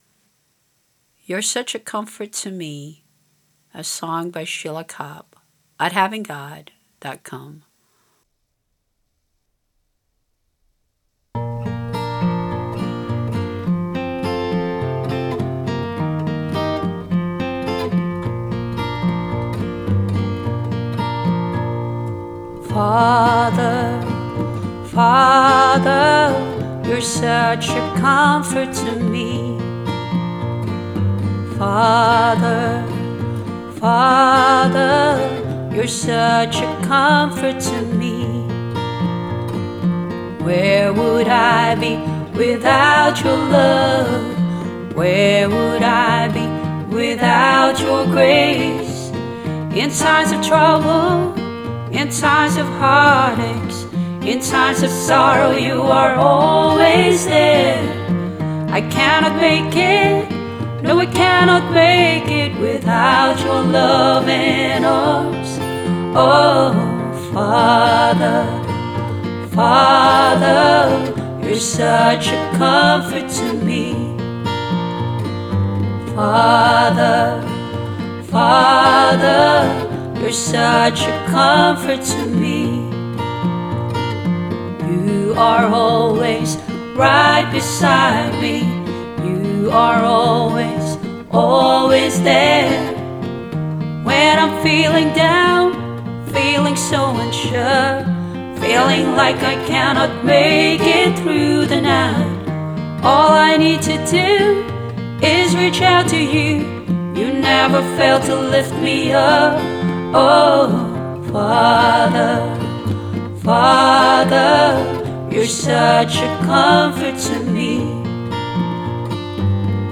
Voices, guitar and bongos